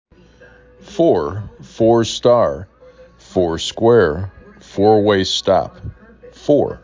four 3 /f/ /aw/ /r/ CLOSED Frequency: 764
4 Letters, 1 Syllable
f aw r